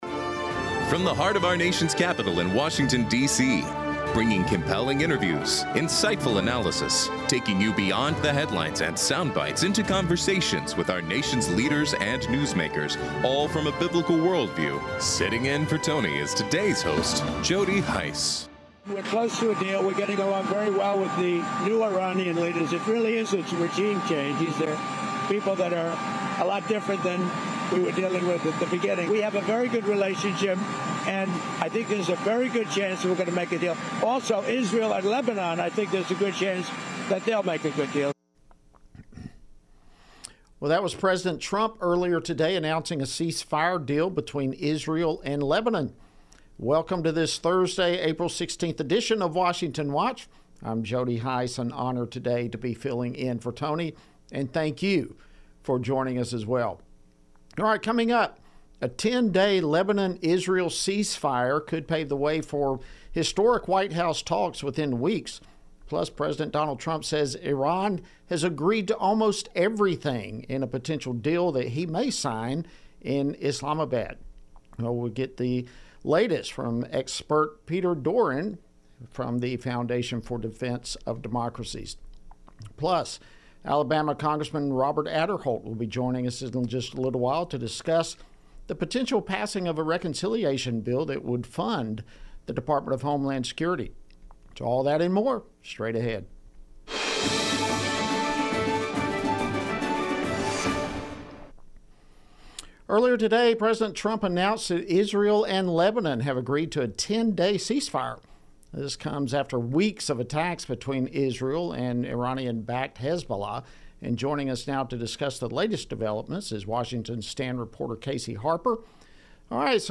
Hard hitting talk radio never has been and never will be supported by the main stream in America!